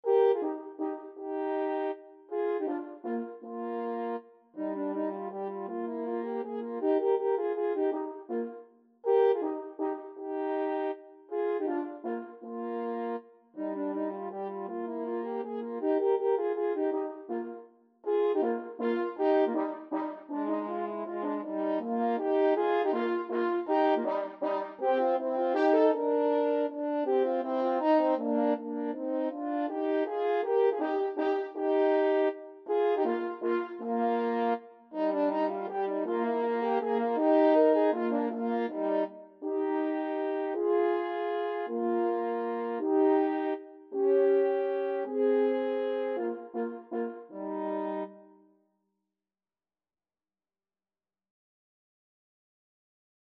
Free Sheet music for French Horn Duet
Eb major (Sounding Pitch) Bb major (French Horn in F) (View more Eb major Music for French Horn Duet )
3/8 (View more 3/8 Music)
Menuett =160
French Horn Duet  (View more Intermediate French Horn Duet Music)
Classical (View more Classical French Horn Duet Music)